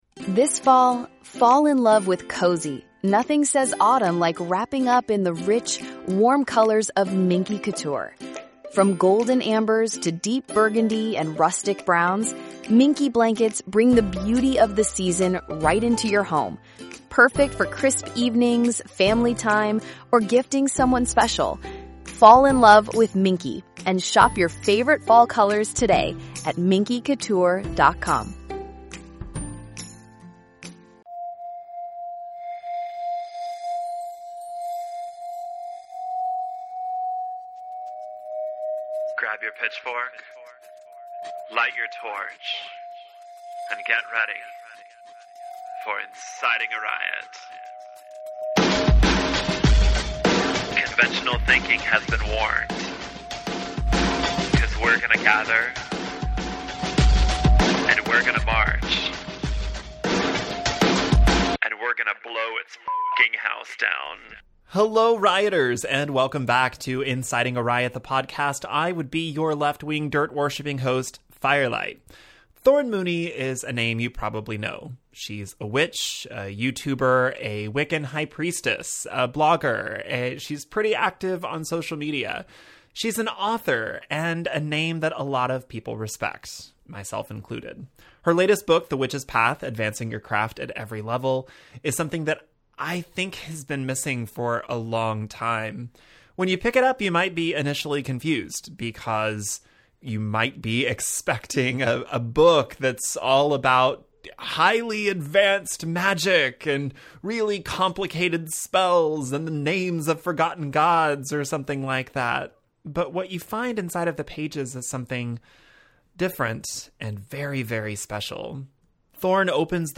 It's a long conversation in which we interview each other about our respective work, our goals, and where we each want to go from here.